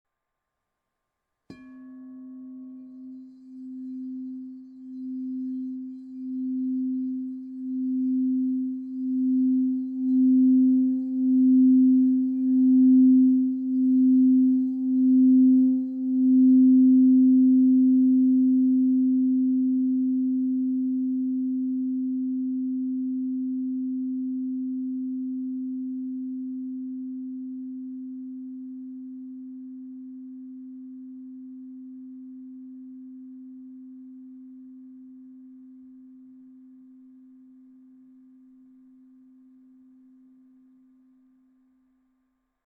Meinl Sonic Energy 10" Marble Crystal Singing Bowl C4, Red, 432 Hz, Root Chakra (MCSB10C)
The Meinl Sonic Energy Marble Crystal Singing Bowls made of high-purity quartz create a very pleasant aura with their sound and design.